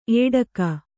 ♪ ēḍaka